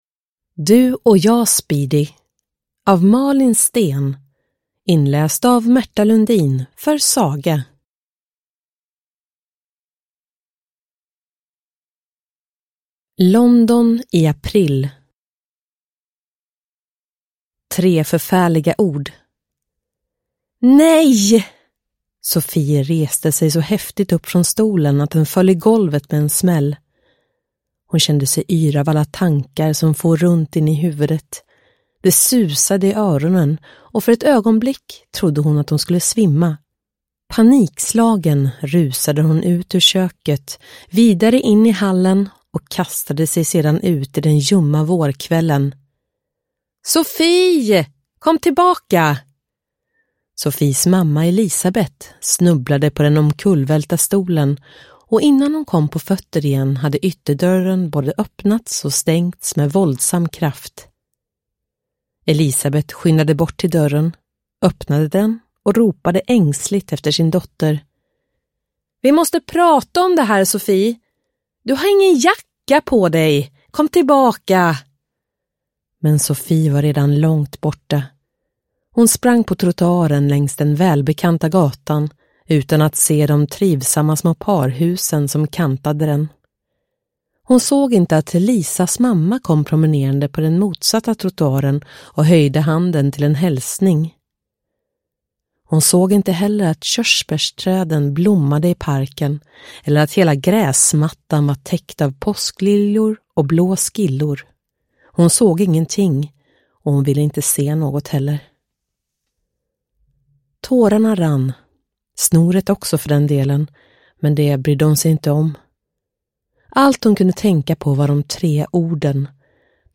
Du och jag, Speedy – Ljudbok – Laddas ner